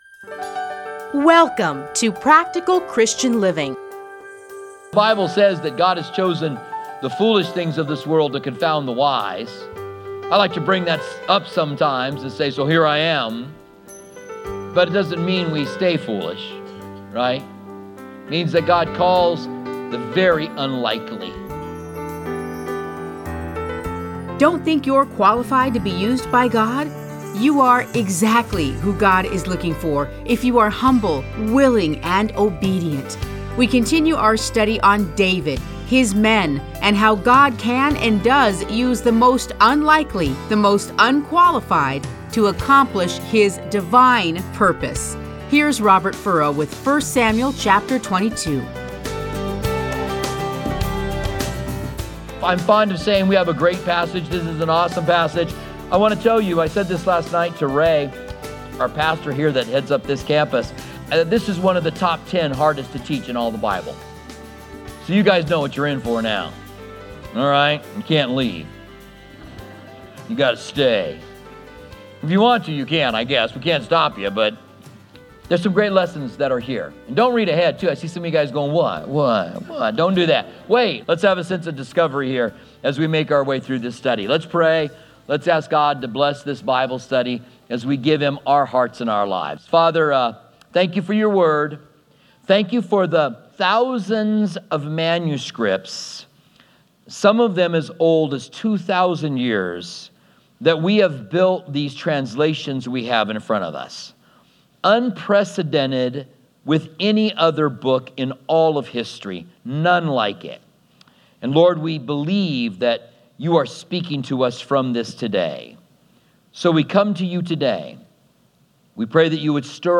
Listen to a teaching from 1 Samuel 22:1-23.